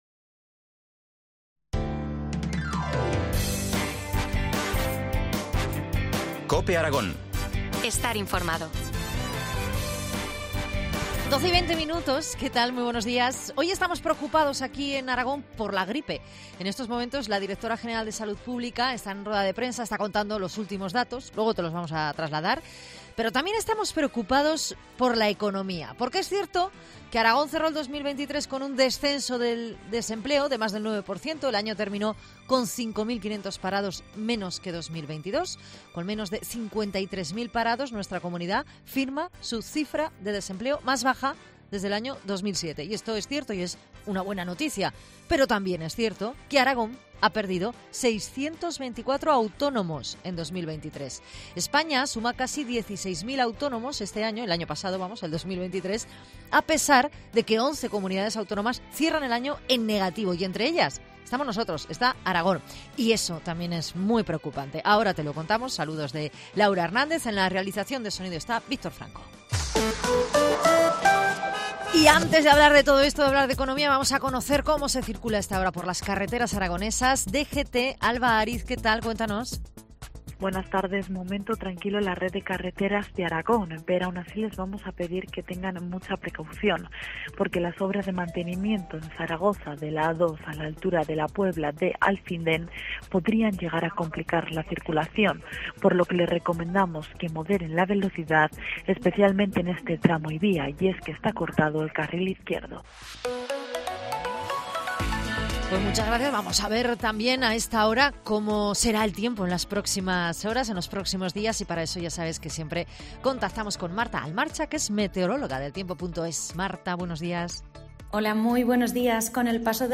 AUDIO: Entrevista del día en COPE Aragón